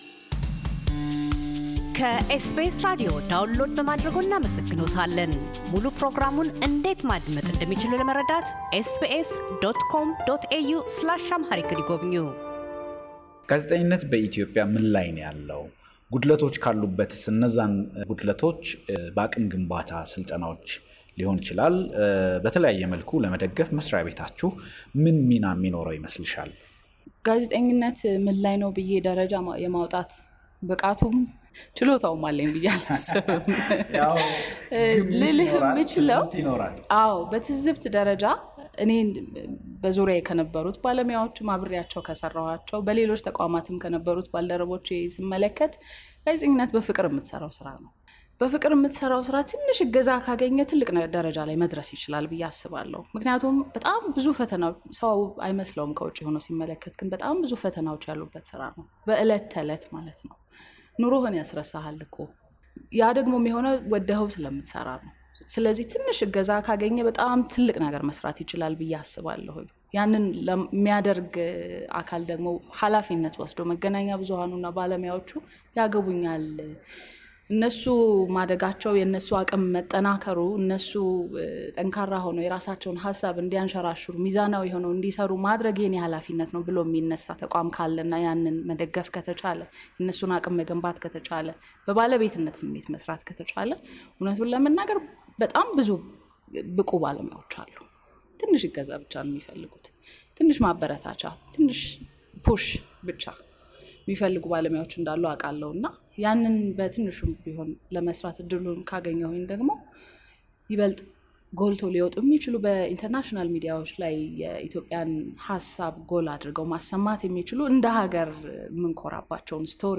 ሰላማዊት ካሣ - የኢፌዲሪ የመንግሥት ኮሙኒኬሽን አገልግሎት ሚኒስትር ደኤታ፤ በመረጃ ፍሰት ውስጥ ስላሉና ወደፊትም ስለሚገጥሙ ተግዳሮቶችንና ግለ ሕይወታቸውን አንስተው ይናገራሉ።